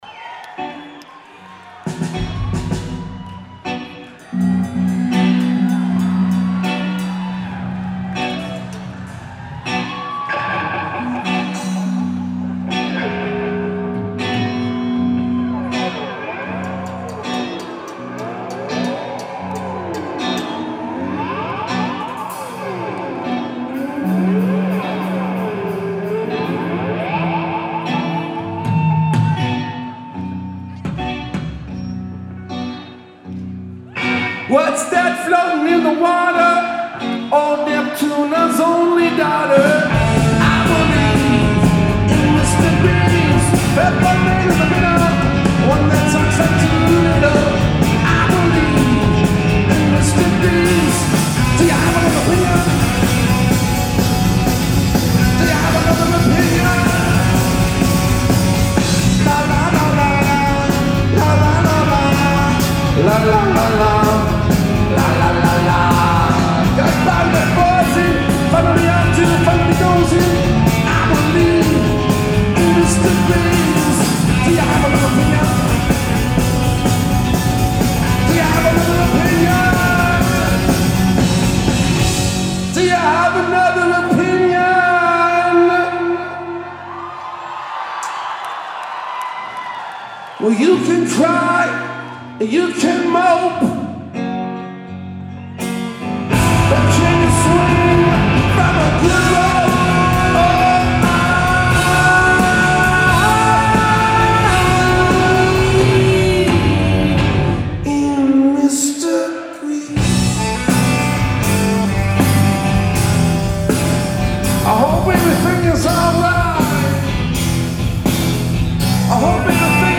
Live at the Wang Theatre / Citi Center
Audience recording
Mics = DPA 4061 > Custom BB > R09HR @ 24/96
Location = Front Row Mezzanine